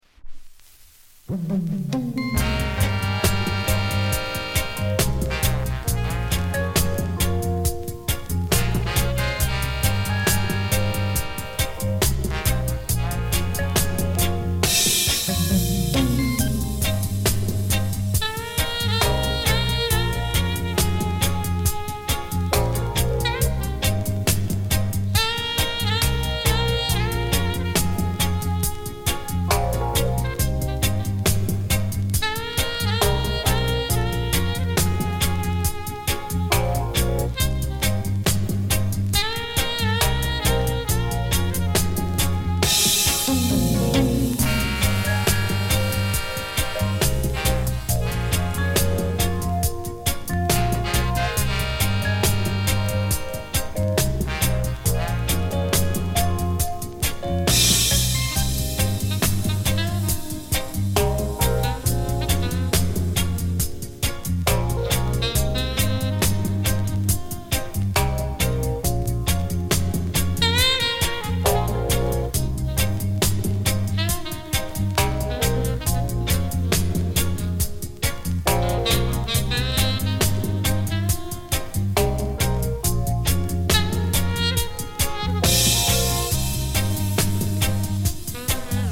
DUB, セサミストリート *
多少 ヒス・ノイズ 乗りますが、曲の間はほぼ気になりません。